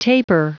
Prononciation du mot tapir en anglais (fichier audio)
Prononciation du mot : tapir